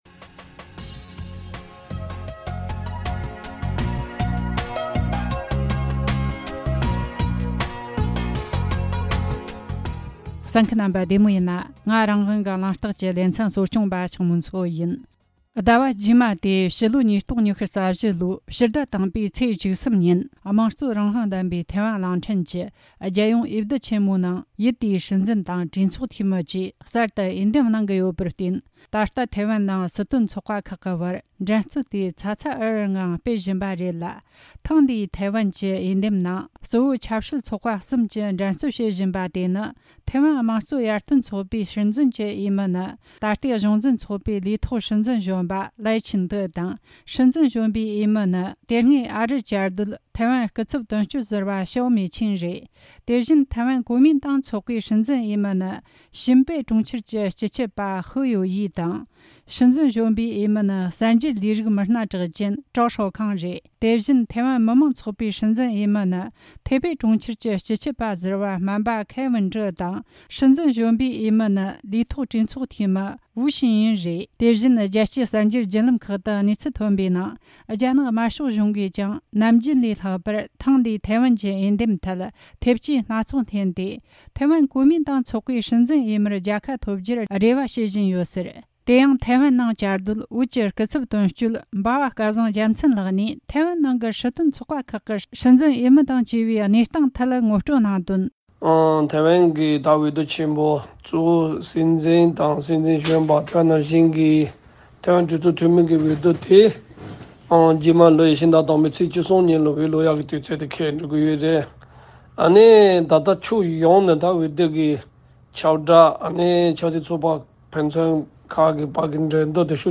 འབྲེལ་ཡོད་ཆེད་མཁས་པར་བཅར་འདྲི་དང་གནད་དོན་དབྱེ་ཞིབ་བྱས་བར་གསན་རོགས་གནོངས།